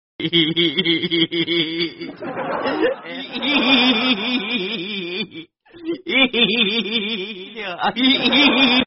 Dog Laughing Hihihi Sound Button: Unblocked Meme Soundboard